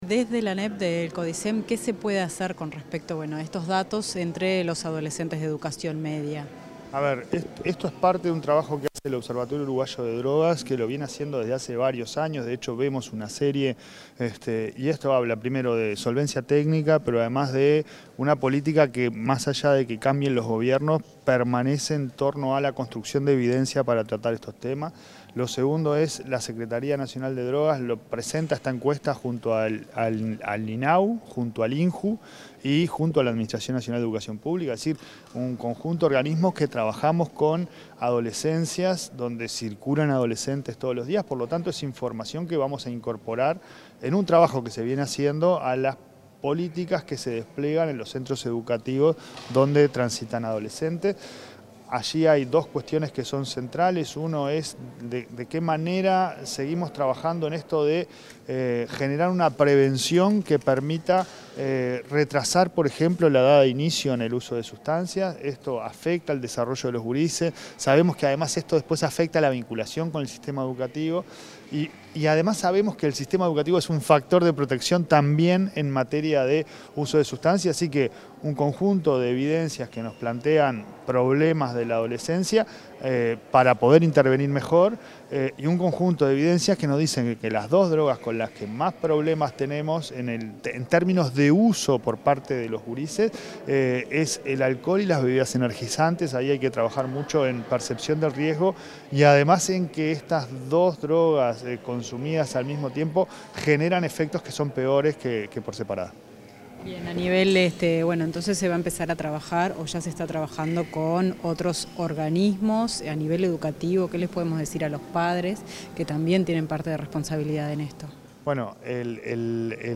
Declaraciones del presidente de la ANEP, Pablo Caggiani